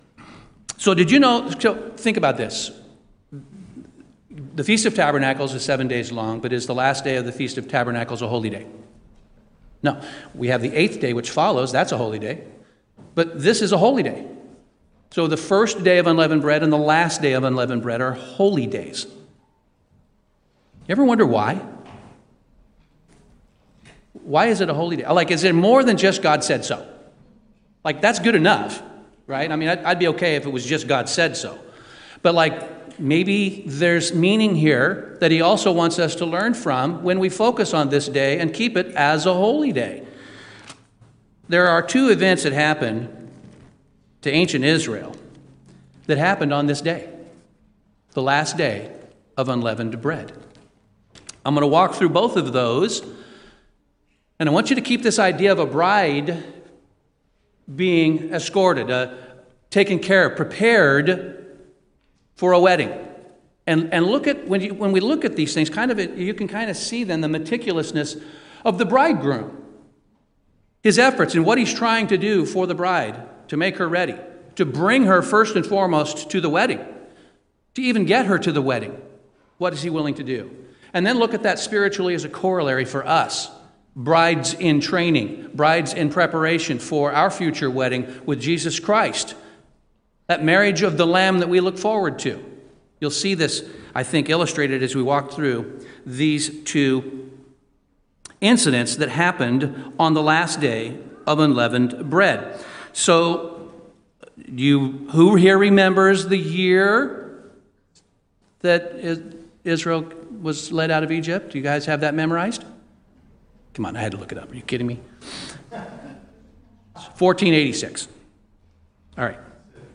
"Walking Forward" reveals the profound connection between two miraculous events that both occurred on the Last Day of Unleavened Bread—the parting of the Red Sea (1486 BC) and the fall of Jericho's impenetrable walls (1446 BC), exactly 40 years apart. Through meticulous biblical chronology and vivid storytelling, the sermon unveils how God twice delivered Israel from impossible situations on this holy day, highlighting that while God creates the path, we must choose to walk through it.